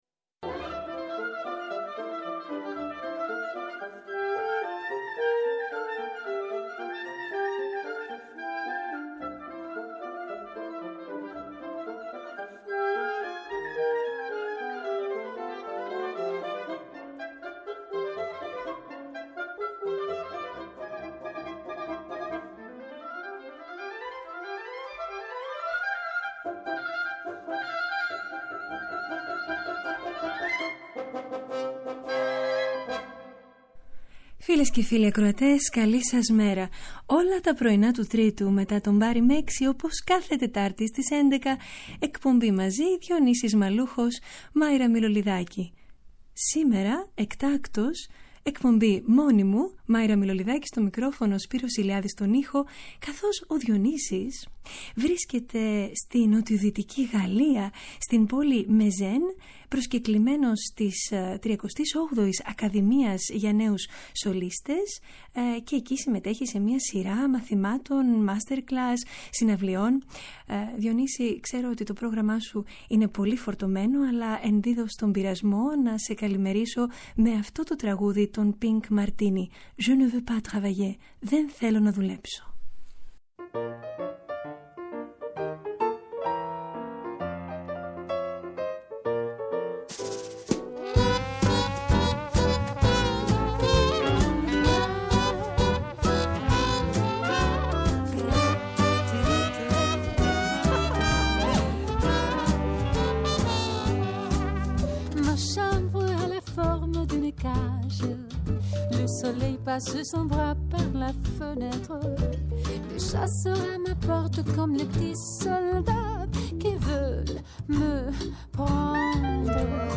Η πολυχρωμία της μουσικής ακρόασης μέσα από ένα ευρύ ρεπερτόριο έργων, με αφορμή την καλλιτεχνική κίνηση της εβδομάδας (συναυλίες, εκθέσεις και παραστάσεις, φεστιβάλ και εκδηλώσεις, νέες κυκλοφορίες ηχογραφήσεων και εκδόσεις.